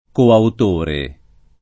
[ koaut 1 re ]